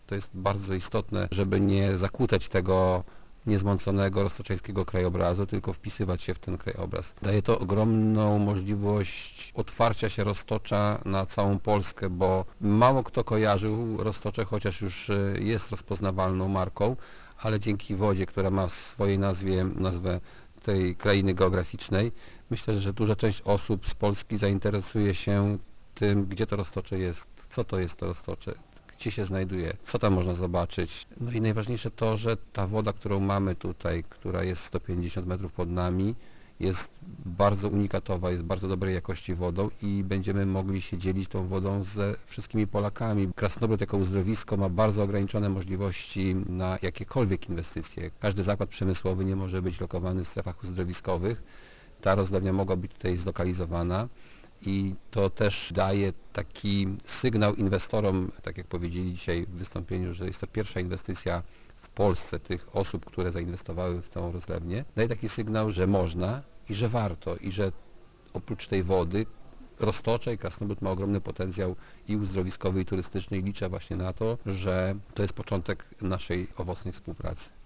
Burmistrz Krasnobrodu Kazimierz Misztal uważa, że powstanie rozlewni w Grabniku może być początkiem otwarcia na inwestycje, szczególnie Roztocza Środkowego, które jest piękne, ale ubogie w takie przedsięwzięcia. Oprócz tego, że nowy zakład spełnia wszystkie wymogi lokalizacji w strefie uzdrowiskowej, to jeszcze nie burzy, a harmonizuje z terenem, na którym powstał - podkreśla burmistrz Misztal: